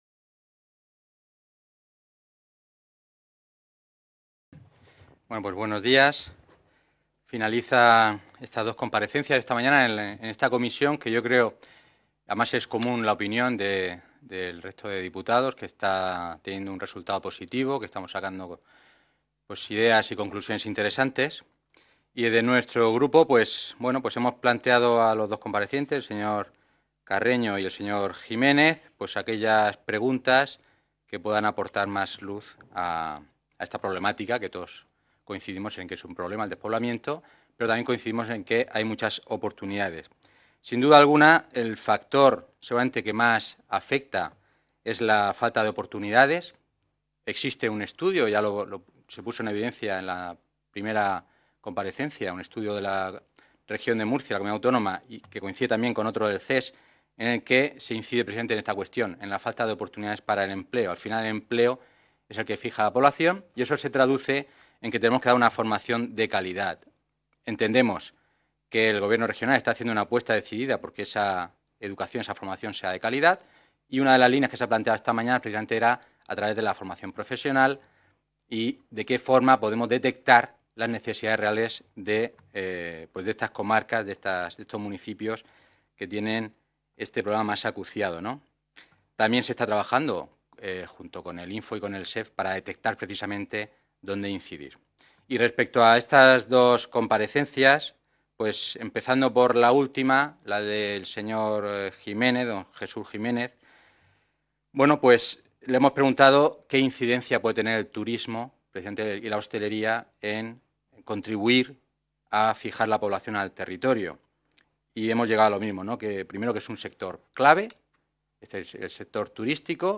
Ruedas de prensa tras la Comisión Especial de Estudio para abordar el Reto Demográfico y la Despoblación en la Región de Murcia
• Grupo Parlamentario Popular